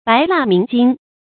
白蠟明經 注音： ㄅㄞˊ ㄌㄚˋ ㄇㄧㄥˊ ㄐㄧㄥ 讀音讀法： 意思解釋： 白蠟：比喻光禿空白。